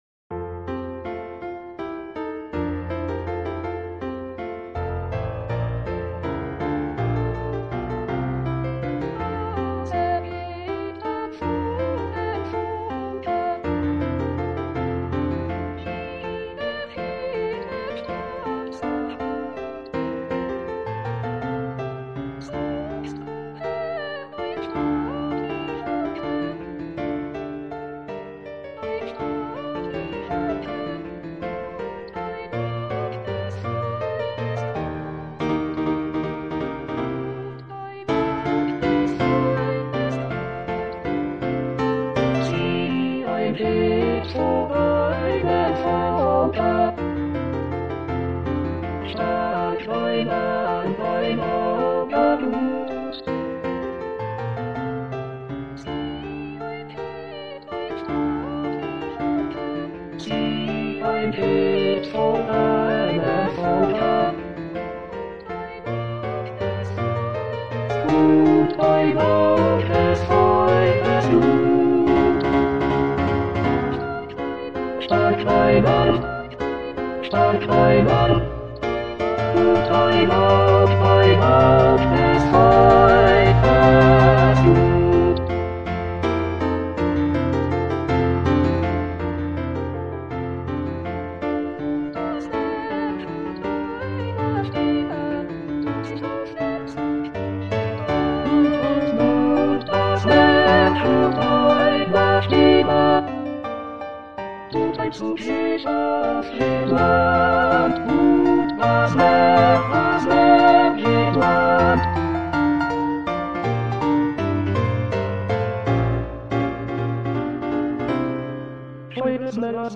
Toutes les voix